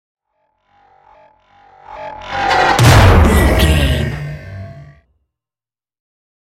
Sci fi whoosh to hit horror
Sound Effects
Atonal
dark
futuristic
intense
tension
woosh to hit